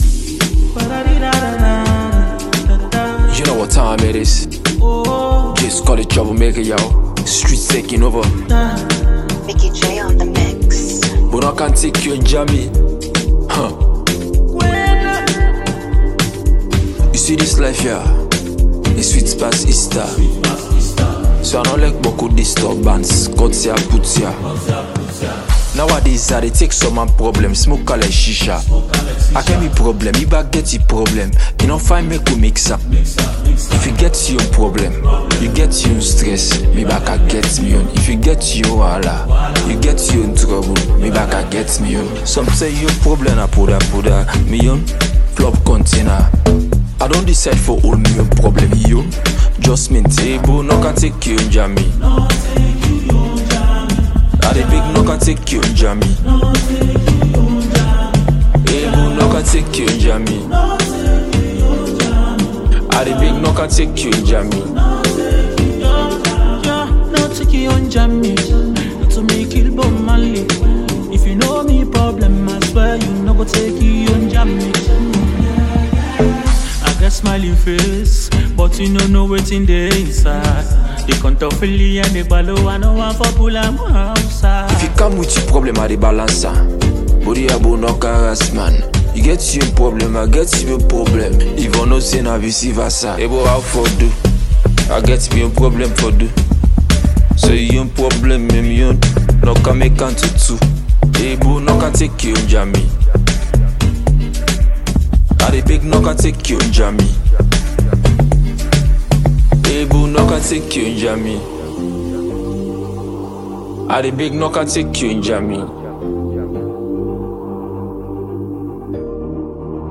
Sierra Leonean Afro rap pop star